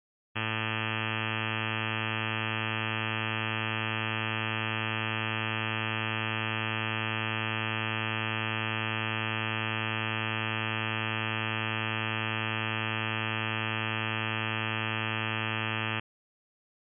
Generic additive synthesis with a sine basis and addition (p. 271)